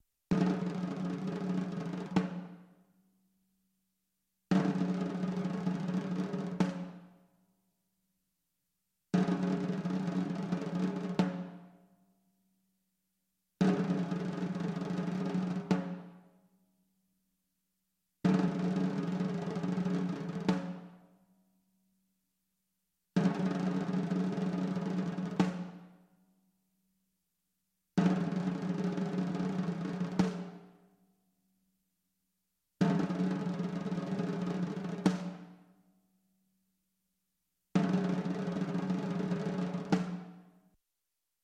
Звуки казни
Звук барабанного боя перед казнью